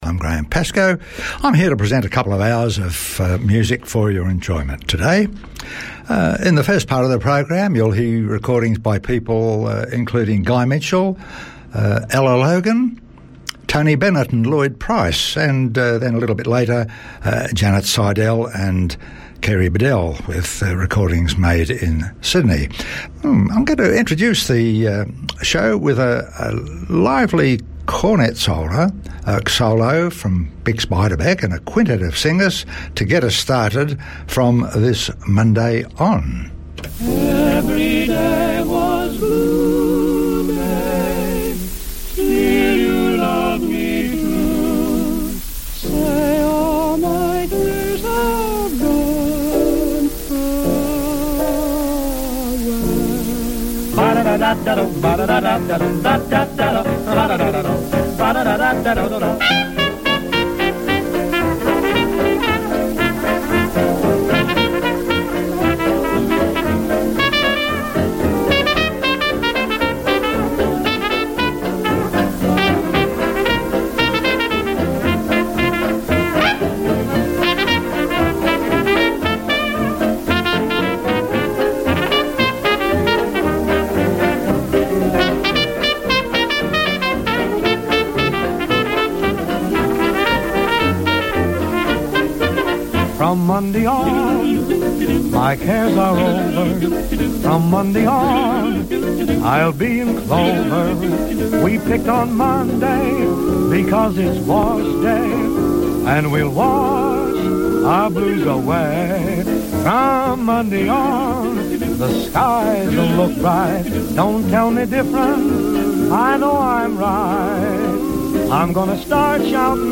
popular music from pre rock & roll eras